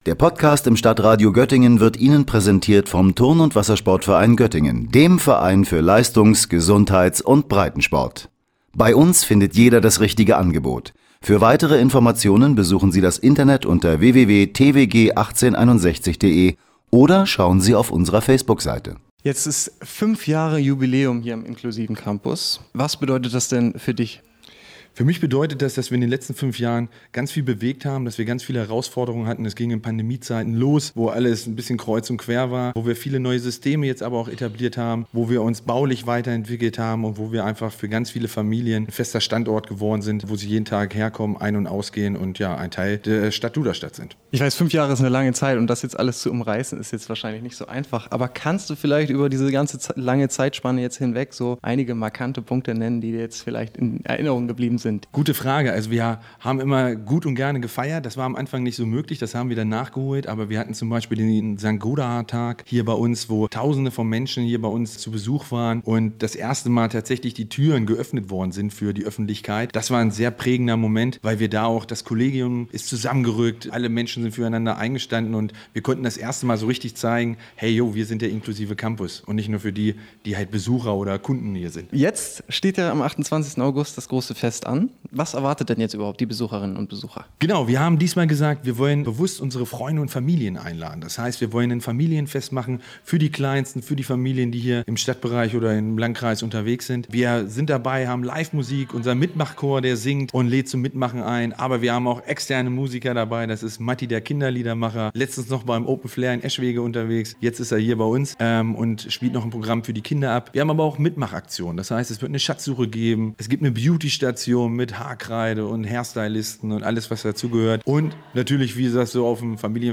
Der Inklusive Campus in Duderstadt feiert sein fünfjähriges Bestehen – mit einer großen Jubiläumsfeier. Seit der Gründung hat sich die Einrichtung zu einem wichtigen Ort für Begegnung, Bildung und Teilhabe entwickelt. Im Gespräch